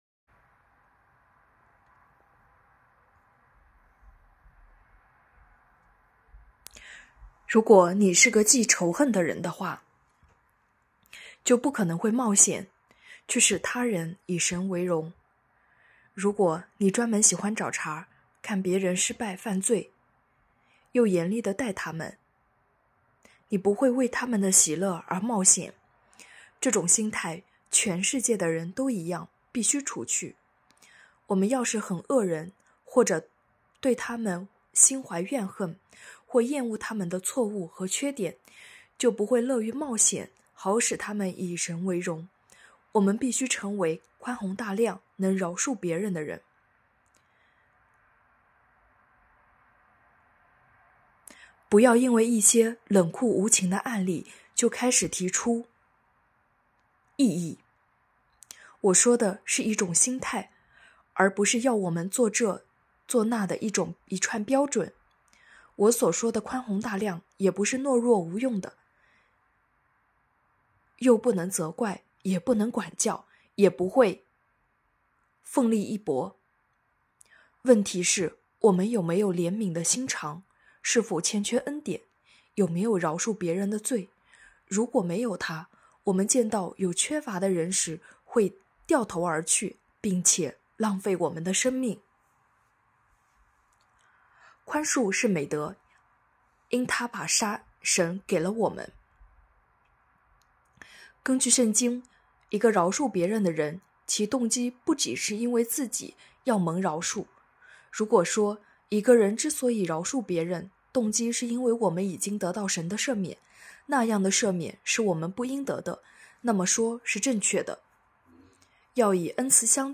2024年3月29日 “伴你读书”，正在为您朗读：《活出热情》 欢迎点击下方音频聆听朗读内容 https